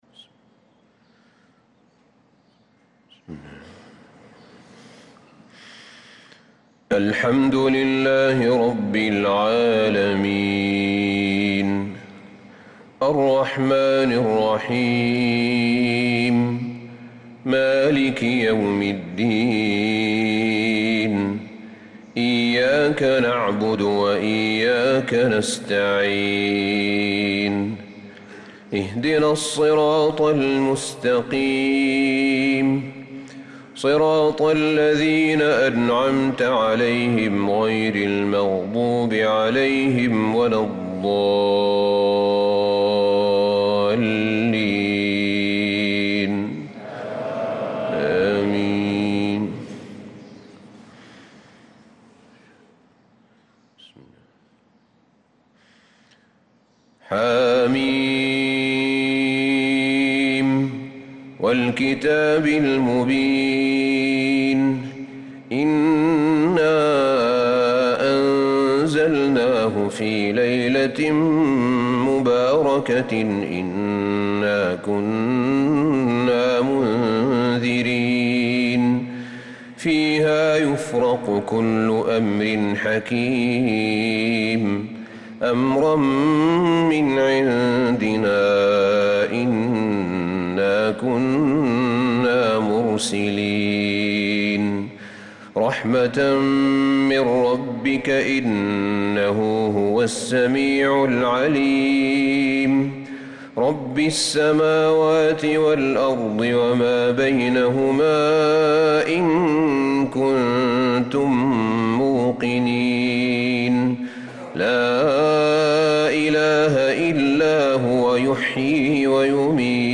صلاة الفجر للقارئ أحمد بن طالب حميد 28 محرم 1446 هـ